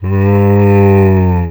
c_zombim2_hit2.wav